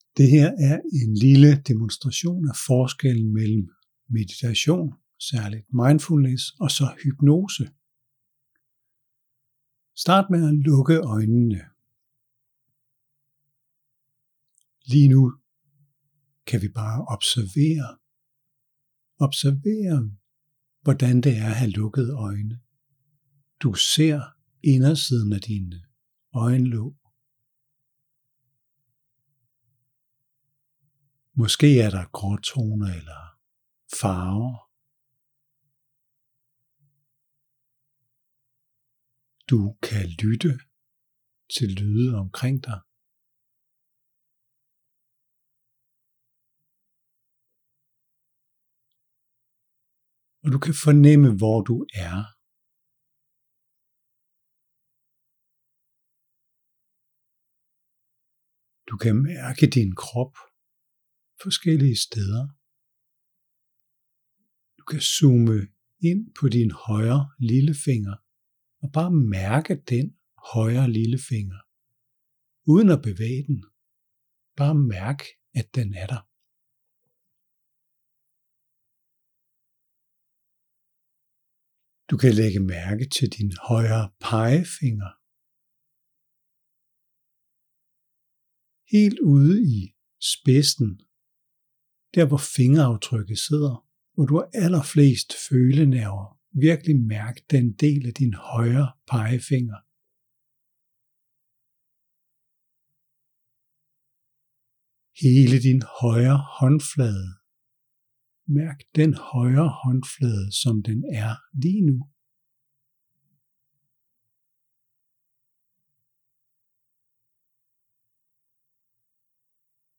Tip: Demo af observerende meditation og påvirkning med hypnose